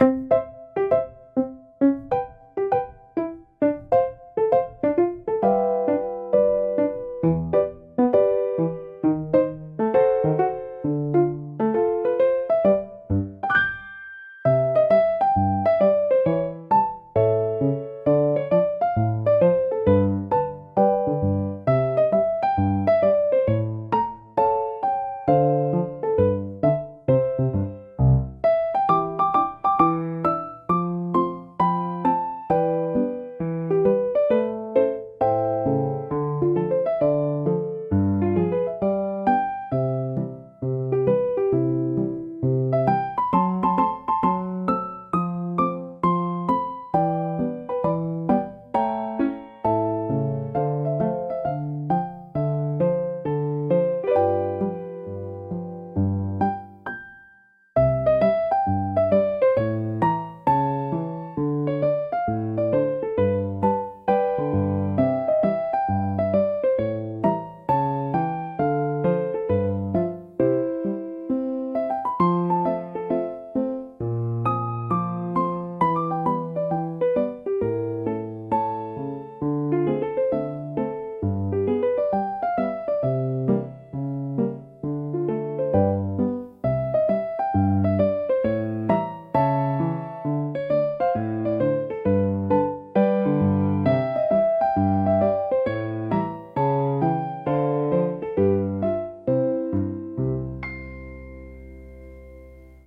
シンプルなメロディラインが心地よいリズムを生み、穏やかながらも前向きなムードを演出します。
ゆったりとした軽快なピアノソロが日常のさりげないシーンを優しく彩るジャンルです。
ピアノの柔らかなタッチが集中をサポートし、疲れを癒す効果を発揮します。